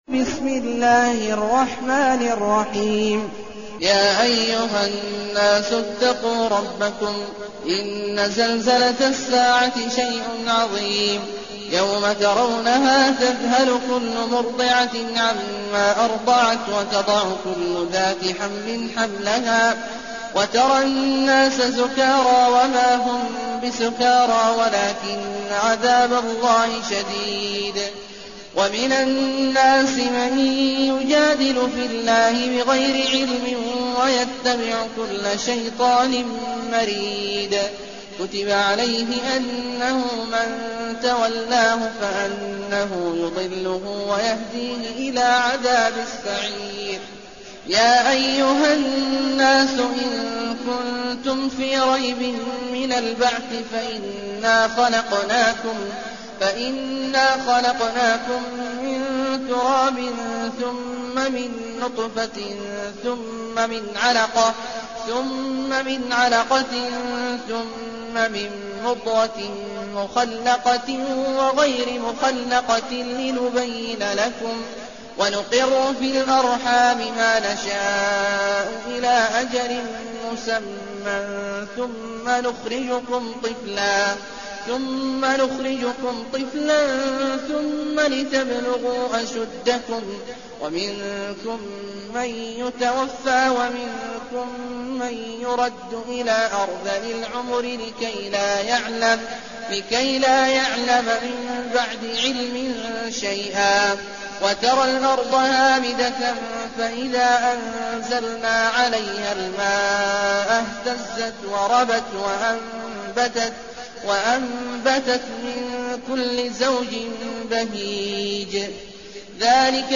المكان: المسجد النبوي الشيخ: فضيلة الشيخ عبدالله الجهني فضيلة الشيخ عبدالله الجهني الحج